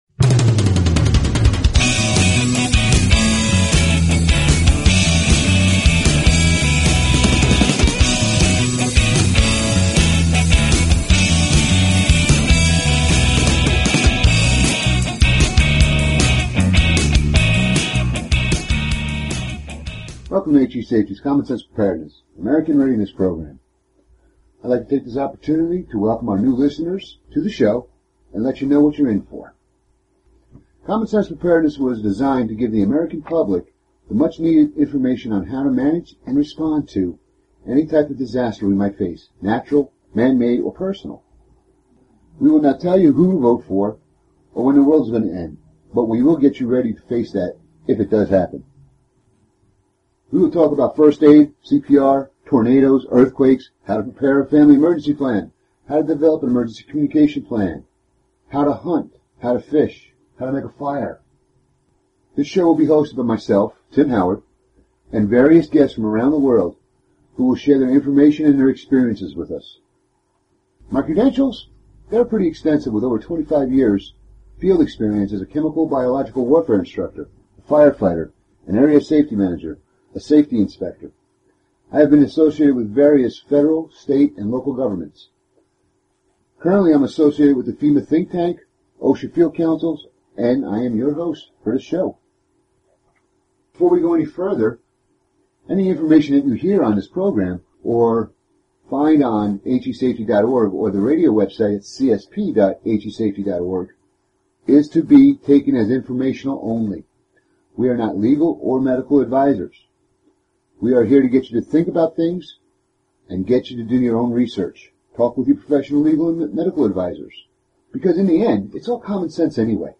Talk Show Episode, Audio Podcast, Common_Sense_Preparedness and Courtesy of BBS Radio on , show guests , about , categorized as